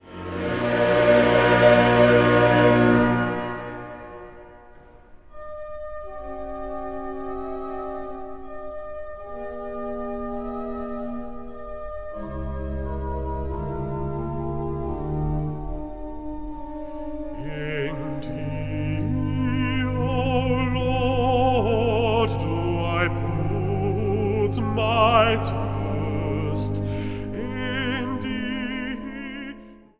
Zang | Mannenkoor